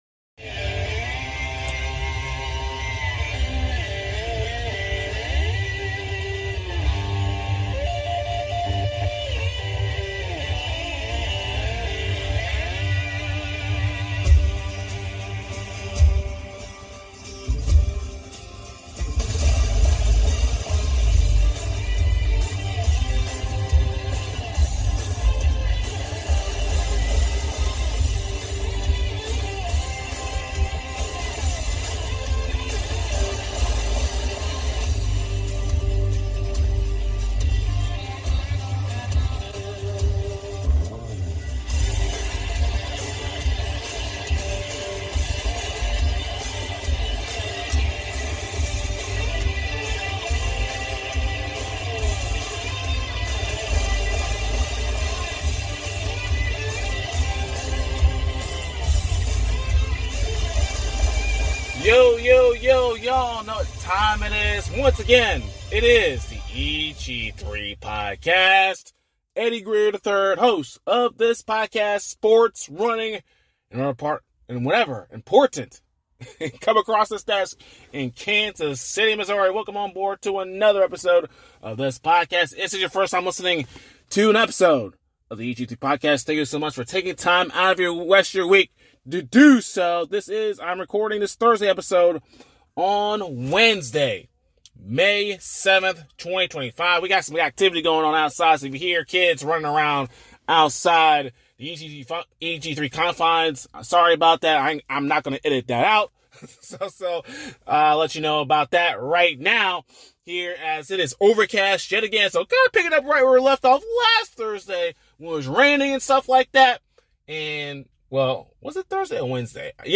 🚨 Welcome to Rink Wide Vancouver, providing LIVE post-game coverage after every Canucks game 🚨